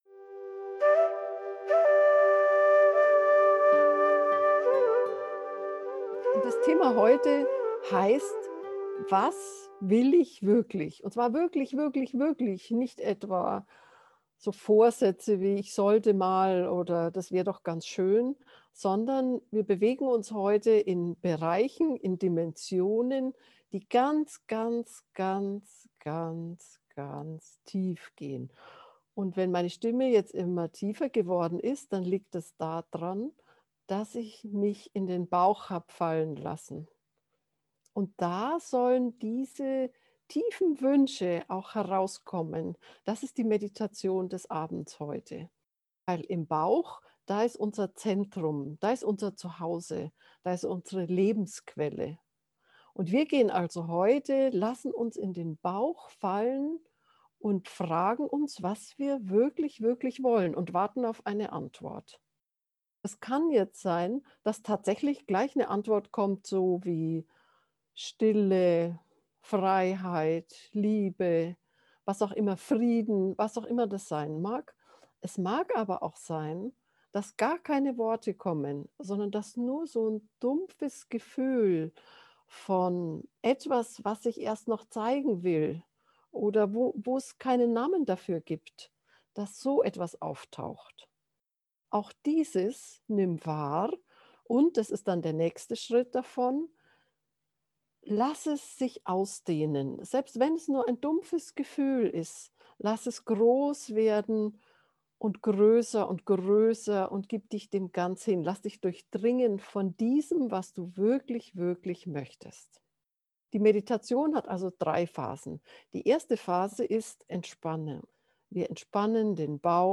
Falls nicht, dann mach diese geführte Meditation. Du entspannst den Bauch, lässt los und fragst dich: Was will ich wirklich in meinem Leben?
was-du-wirklich-willst-gefuehrte-meditation